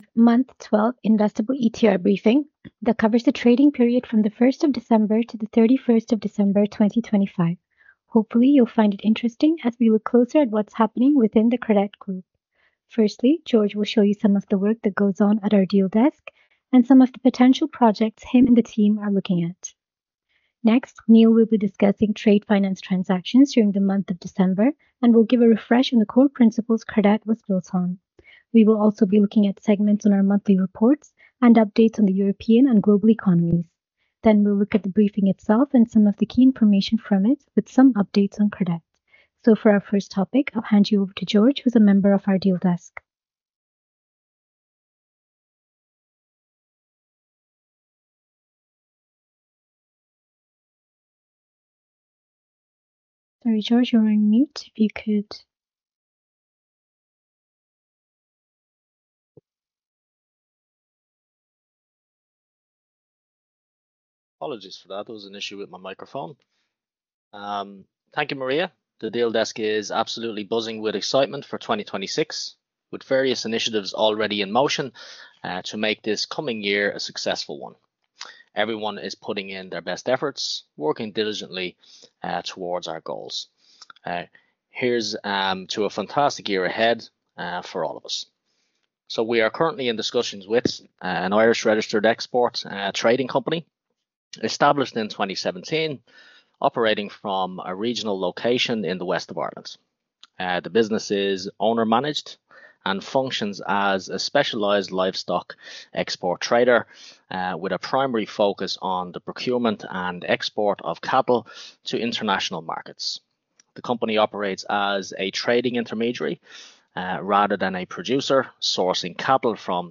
INVESTOR CONFERENCE CALL RECORDINGS
Listen to the 2024-M06 Investor conference call